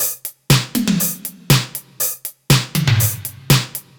Index of /musicradar/french-house-chillout-samples/120bpm/Beats
FHC_BeatC_120-03_NoKick.wav